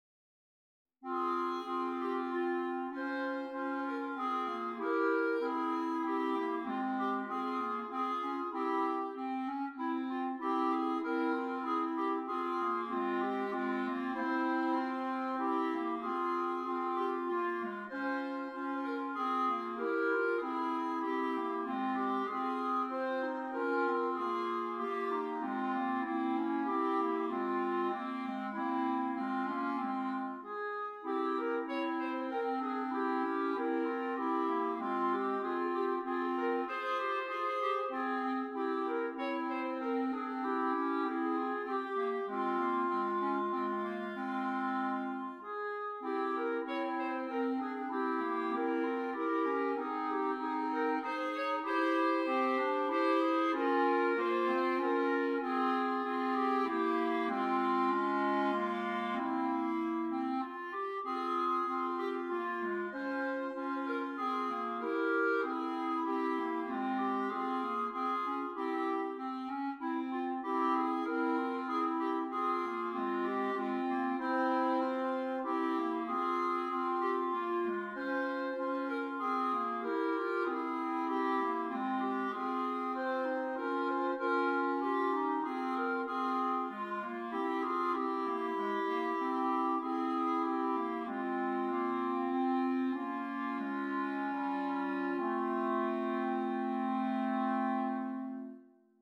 3 Clarinets
introspective, melancholy work